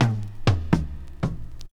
25DR.BREAK.wav